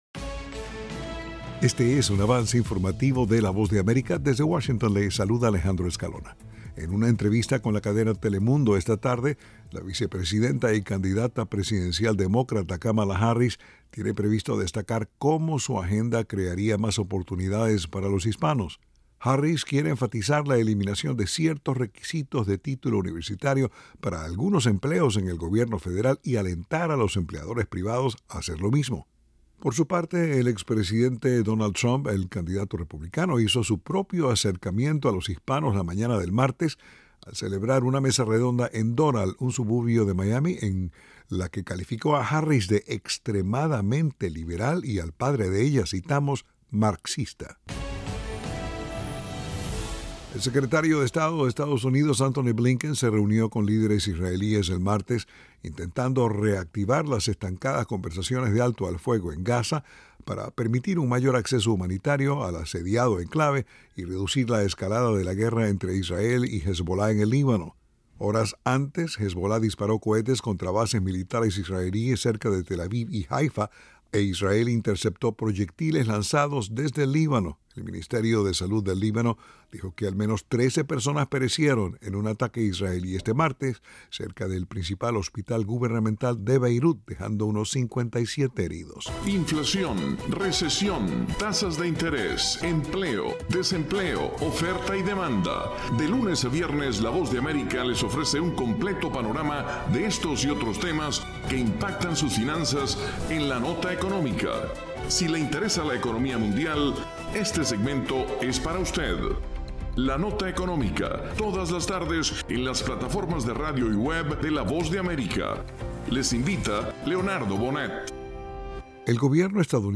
Avance Informativo
El siguiente es un avance informativo presentado por la Voz de América en Washington.